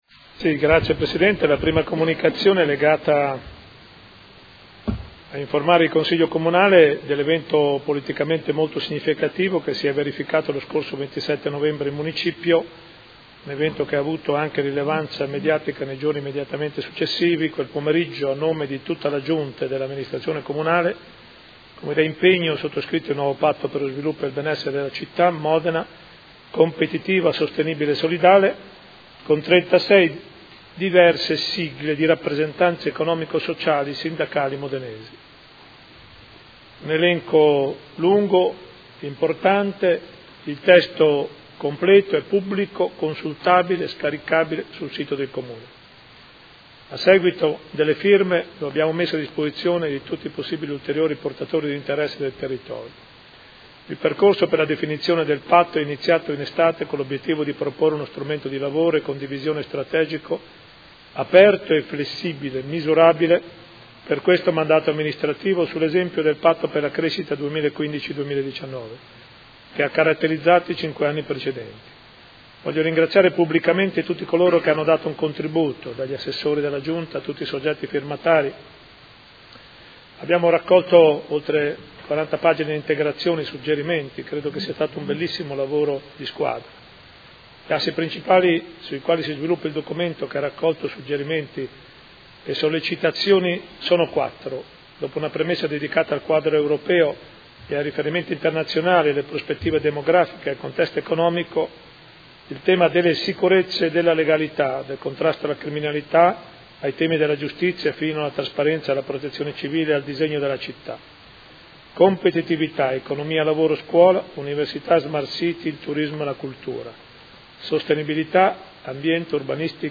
Sindaco — Sito Audio Consiglio Comunale